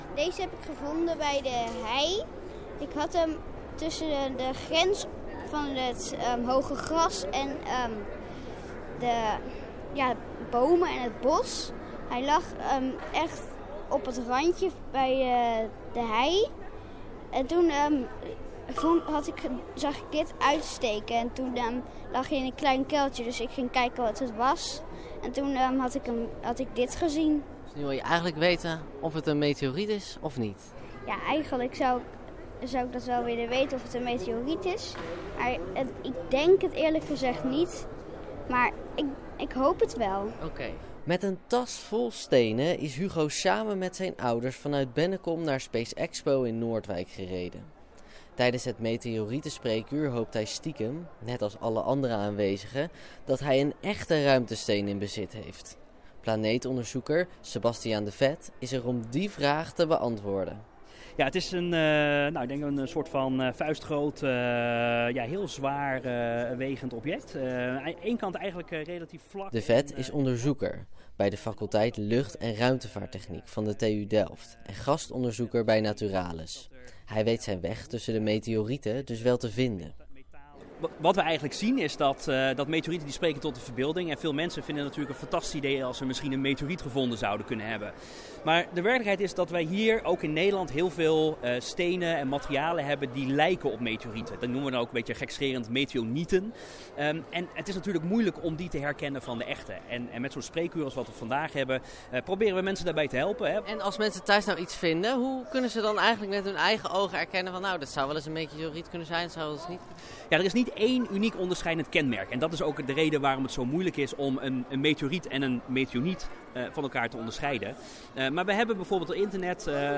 Het ruimtevaartmuseum krijgt vaak vragen van mensen die zelf vermoeden een meteoriet gevonden te hebben. Zaterdag kregen eigenaren van bijzondere stenen de kans om het antwoord te krijgen op die ene vraag: is het een meteoriet of niet?